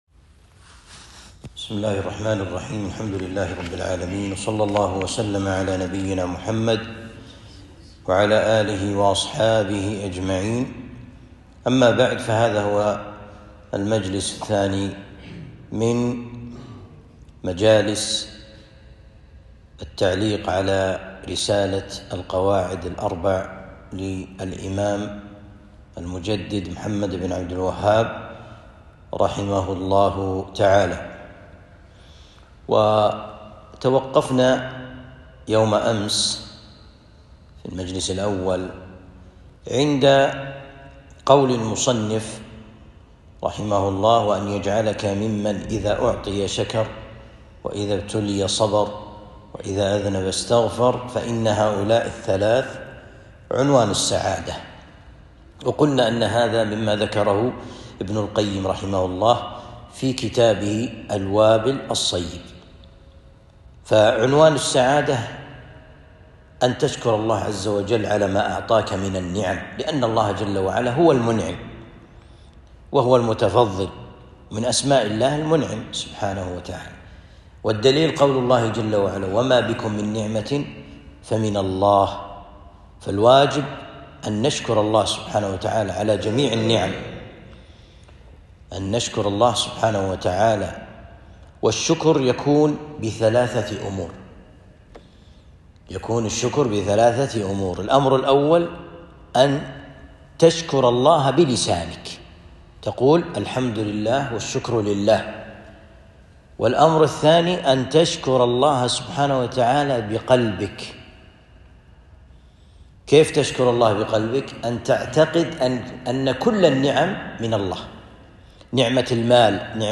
الدرس الثاني من التعليق على كتاب القواعد الأربع